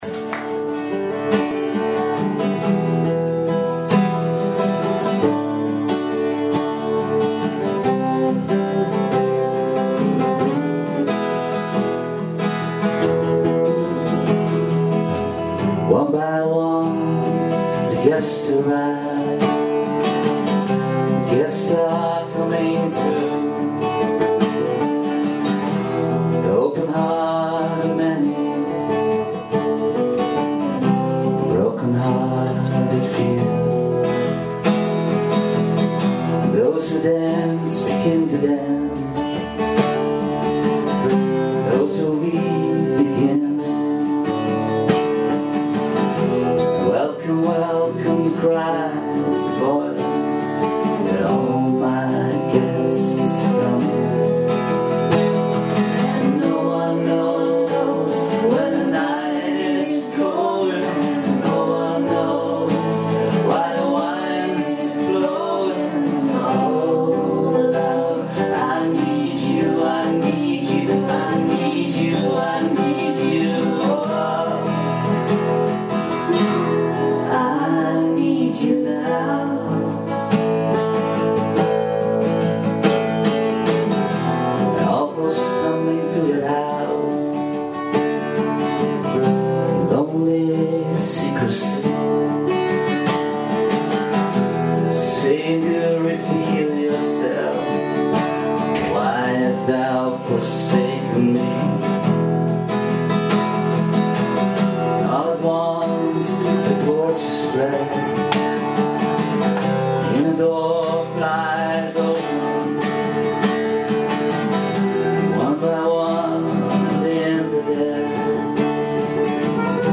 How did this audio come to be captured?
in the record shop Concerto, Amsterdam